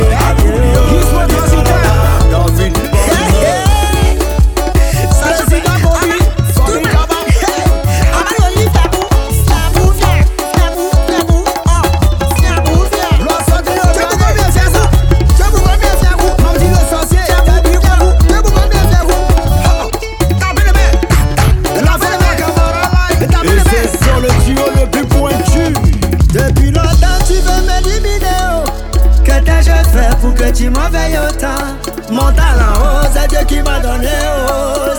Жанр: Африканская музыка
# Afrobeats